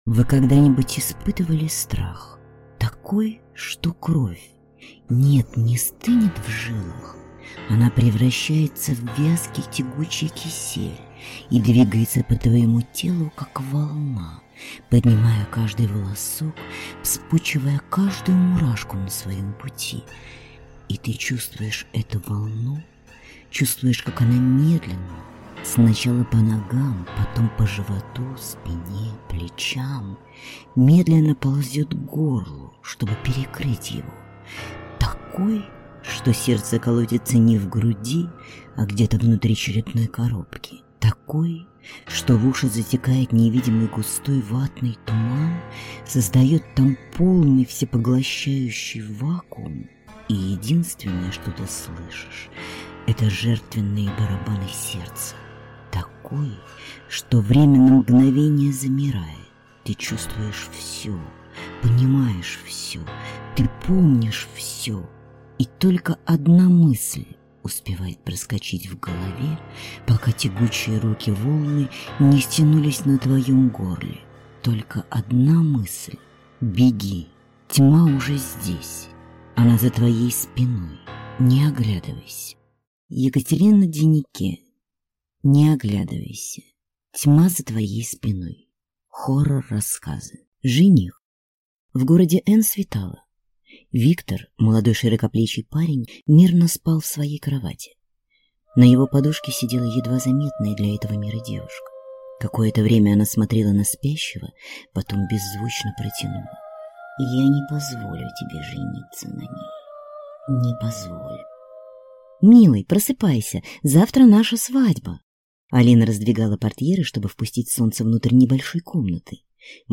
Аудиокнига Не оглядывайся. Тьма за твоей спиной. Хоррор рассказы | Библиотека аудиокниг